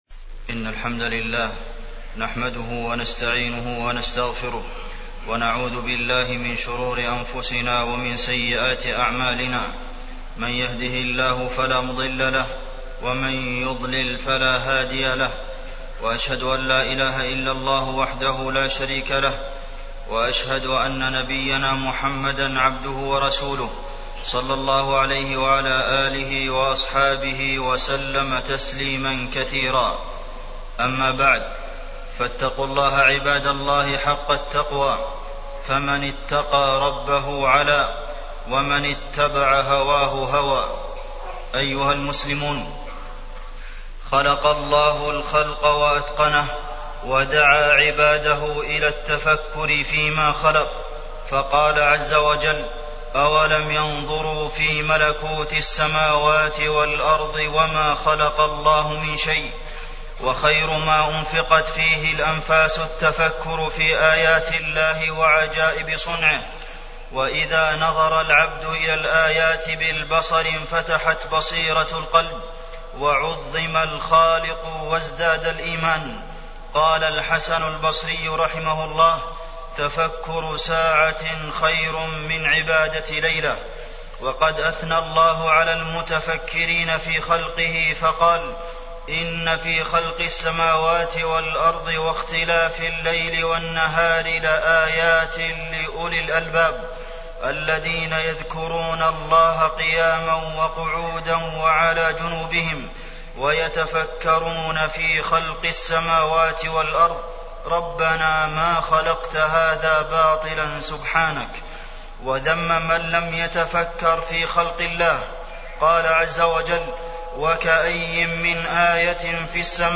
تاريخ النشر ٦ ربيع الأول ١٤٢٩ هـ المكان: المسجد النبوي الشيخ: فضيلة الشيخ د. عبدالمحسن بن محمد القاسم فضيلة الشيخ د. عبدالمحسن بن محمد القاسم التدبر في خلق الله